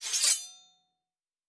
Weapon UI 10.wav